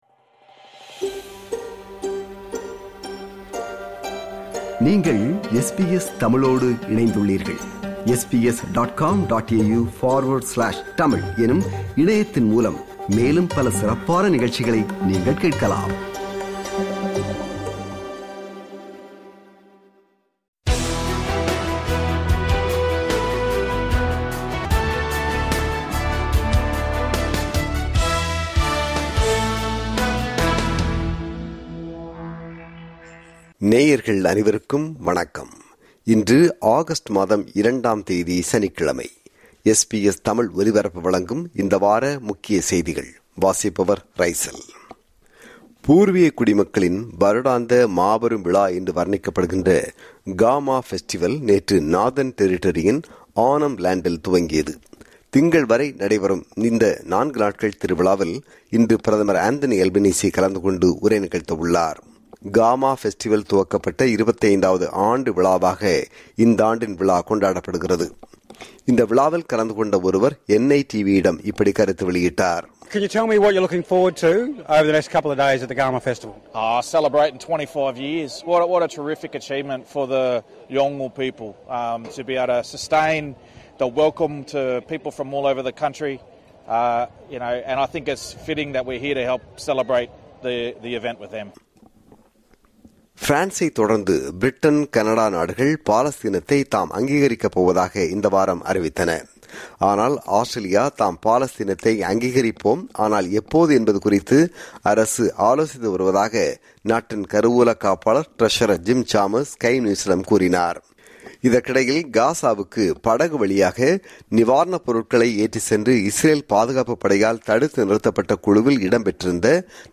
ஆஸ்திரேலிய, உலக செய்திகளின் இந்த வார தொகுப்பு